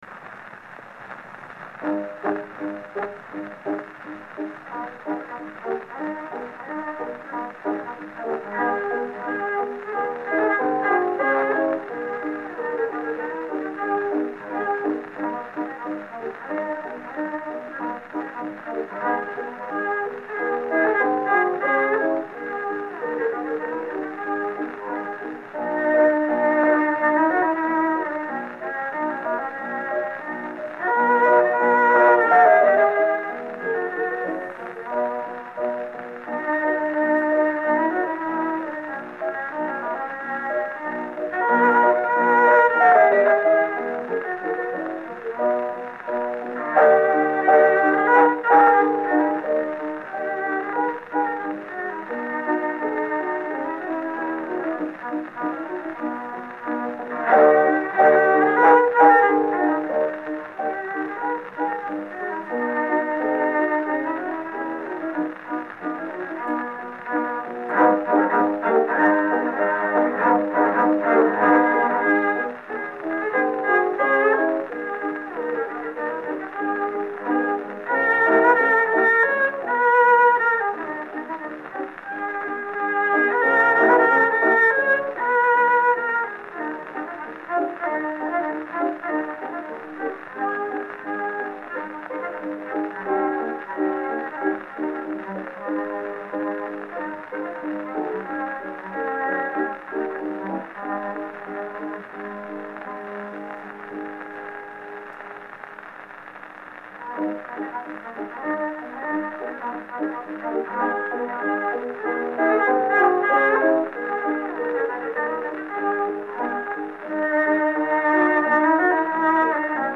The 78’s have been recorded with microphones placed at the end of the horn of the player, (with no edit or cleaning up ).